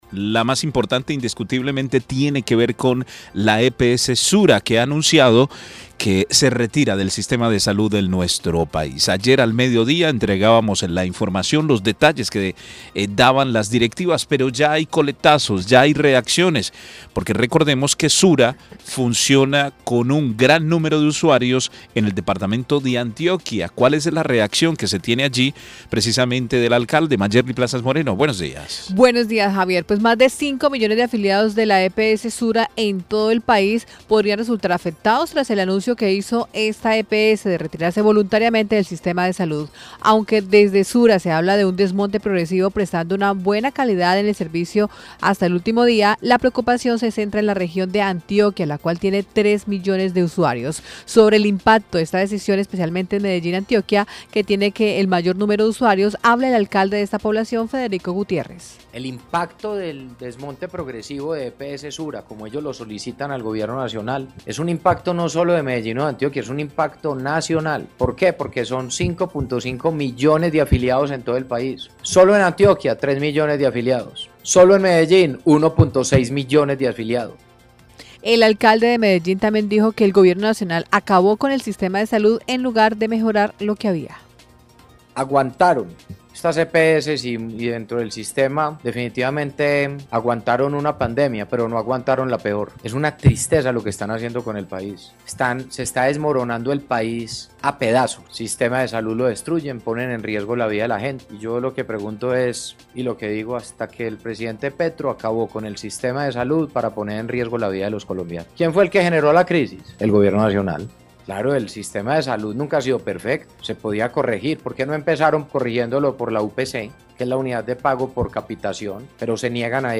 [AUDIO] Alcalde Federico Gutiérrez habla del retiro de la Eps Sura
Sobre el impacto de esta decisión, especialmente Antioquia que tiene el mayor número de usuarios habla El Alcalde de Medellín Federico Gutierrez.